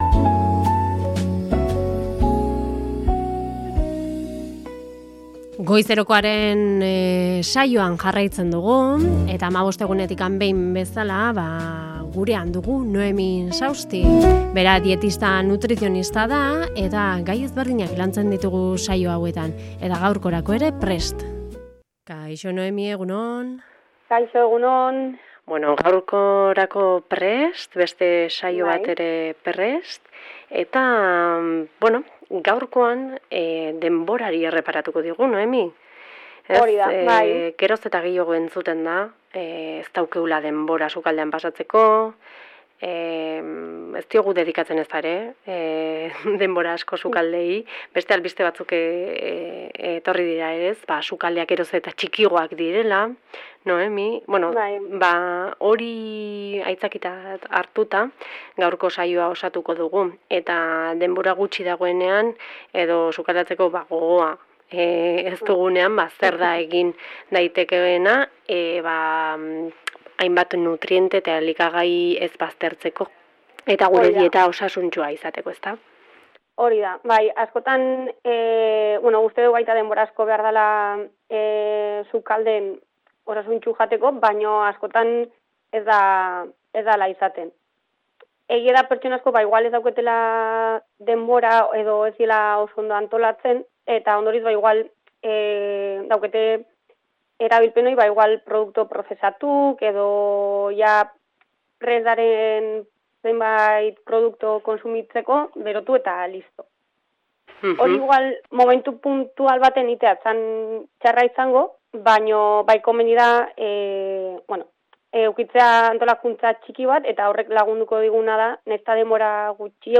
Zuzenean ostiraletan 10:30etan eta ondoren gure web gunean entzungai.